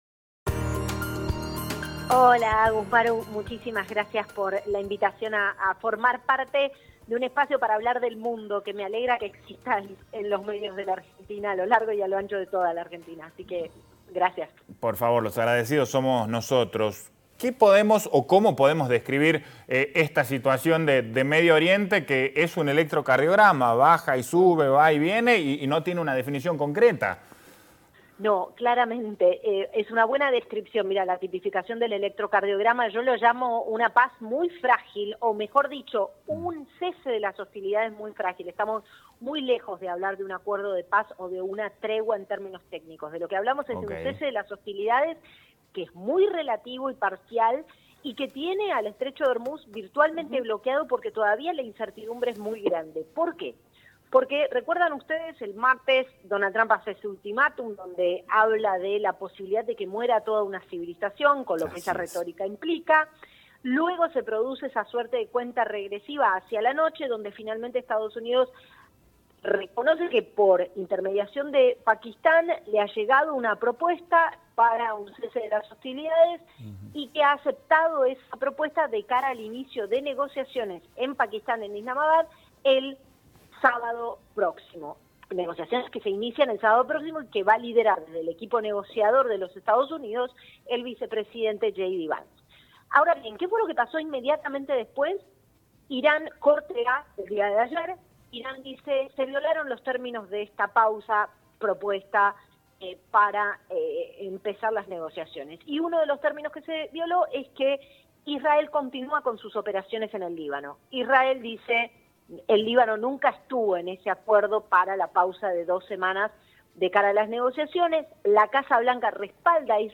Entre los ejes más importantes de la entrevista, la periodista explicó qué representa el estrecho de Ormuz para el flujo global de petróleo y por qué ese punto es clave en el equilibrio energético mundial.